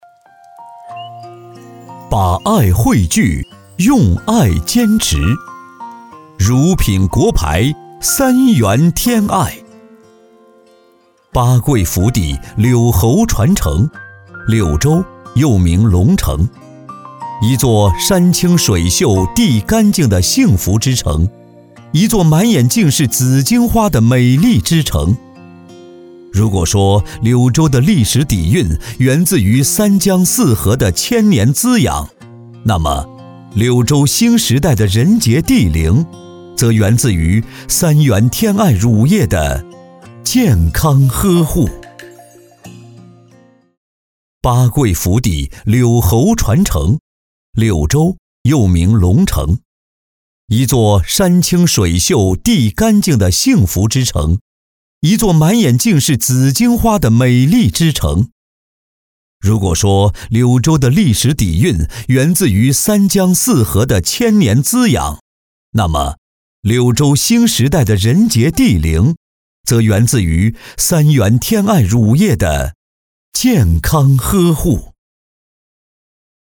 C男153号
【宣传片】 乳品国牌 三元天爱
【宣传片】 乳品国牌  三元天爱.mp3